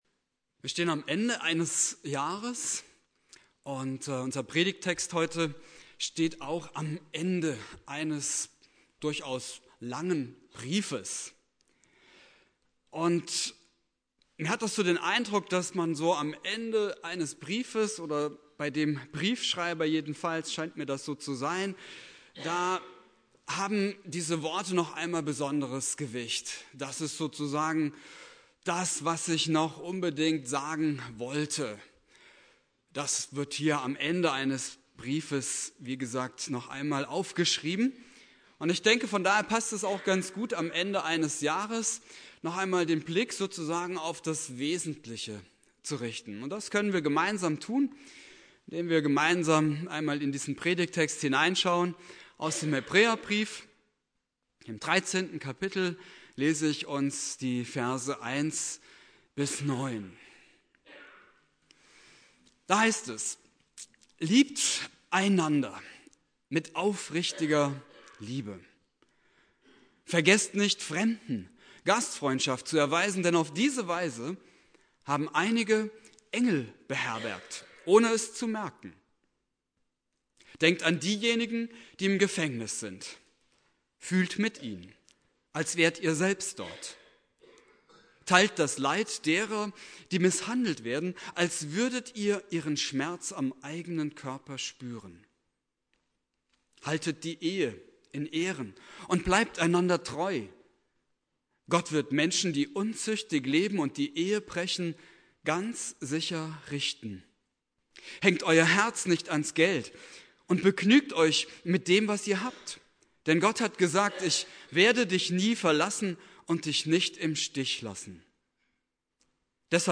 Predigt: Worauf sollen wir achten: 1) die Nächstenliebe 2) die Gastfreundschaft 3) auf verfolgte Christen 4) die Ehe 5) die Finanzen